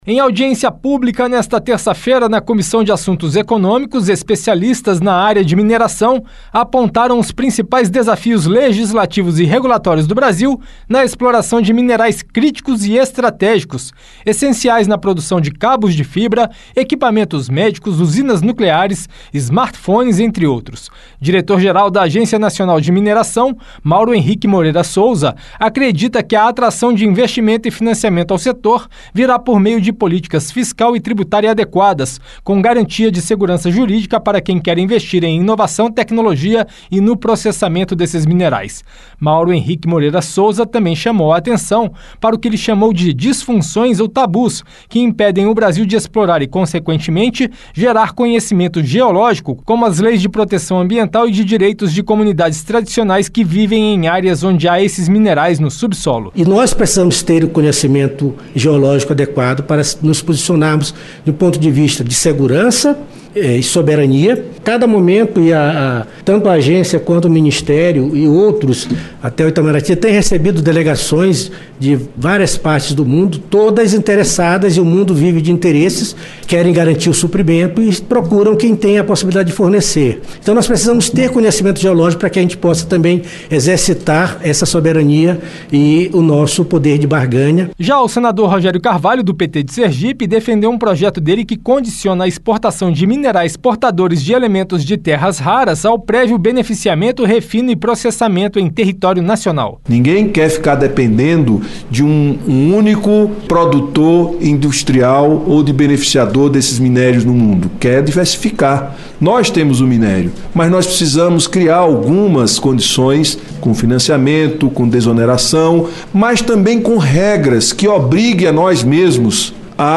Em audiência pública nesta terça-feira (1º), a Comissão de Assuntos Econômicos (CAE) discutiu com especialistas os principais desafios legislativos e regulatórios do Brasil na exploração de minerais críticos e estratégicos, essenciais para a produção de cabos de fibra, equipamentos médicos, equipamentos de defesa, usinas nucleares, smartphones e tecnologias necessárias à transição energética. Regras de licenciamento ambiental e a criação de um cenário propício ao investimento foram alguns dos pontos discutidos.